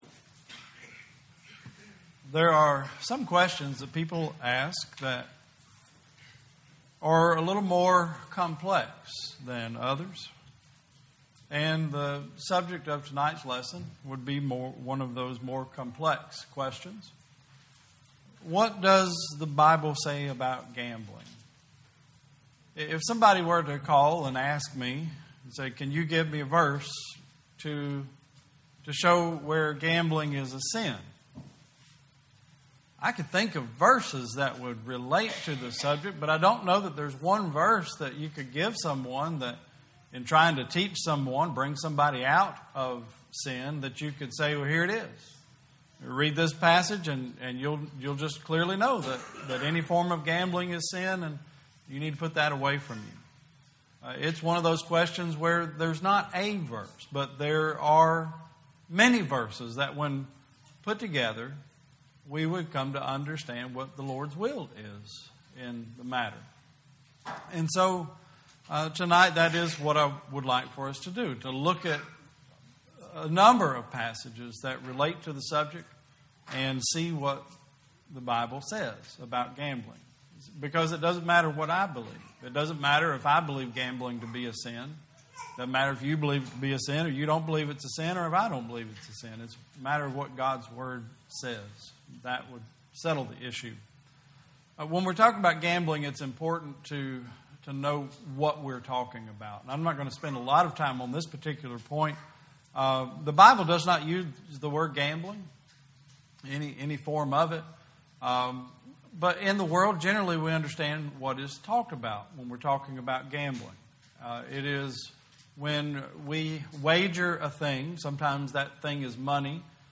2019 Service Type: Sunday Service Topics: gain , Money , Temptation « What Happened to Uzzah What Does the Bible Say About Drinking Alcohol?